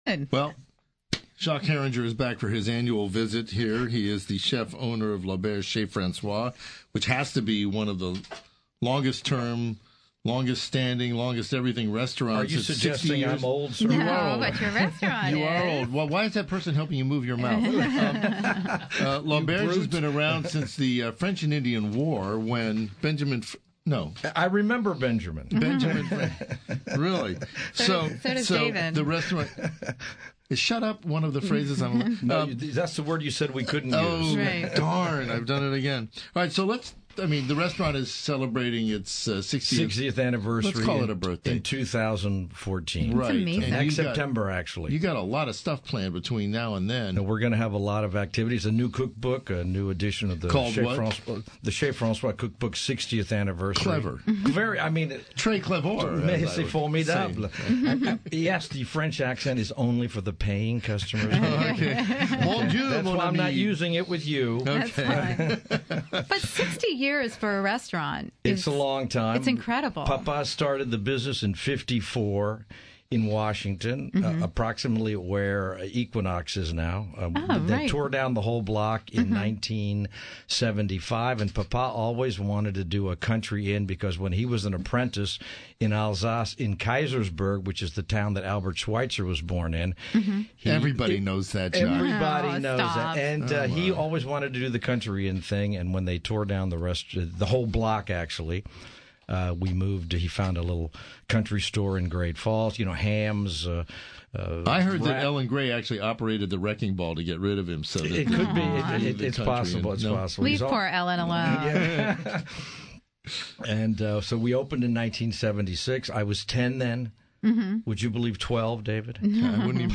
Foodie & the Beast Radio Broadcast - L'Auberge Chez François